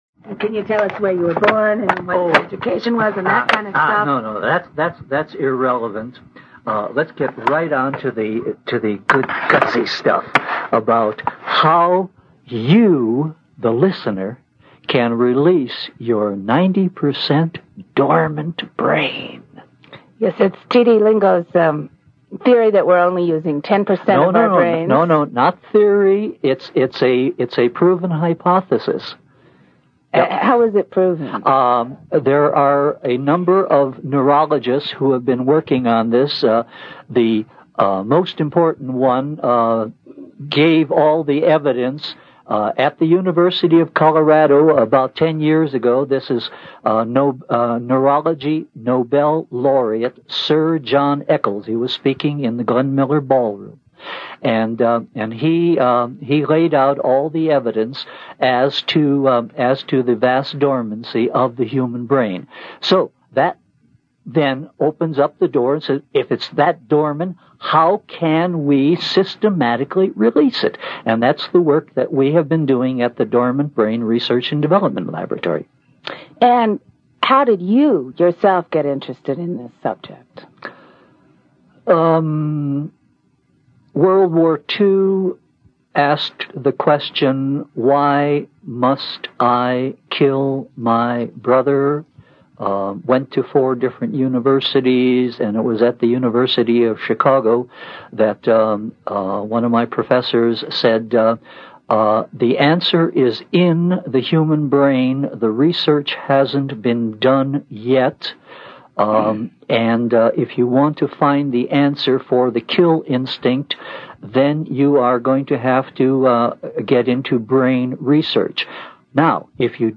The quality is not the finest, but you can feel the man's emotion on the radio and it cuts right through the grainy screen.